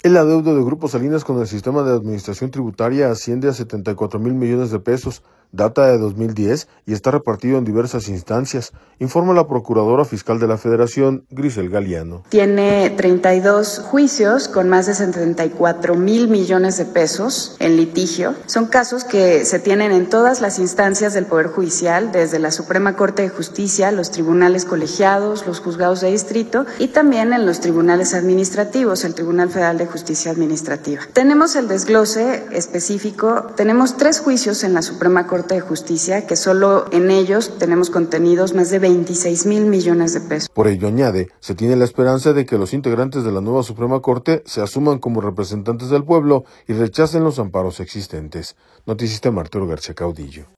El adeudo de Grupo Salinas con el Sistema de Administración Tributaria asciende a 74 mil millones de pesos, data de 2010, y está repartido en diversas instancias, informa la procuradora fiscal de la Federación, Grisel Galiano.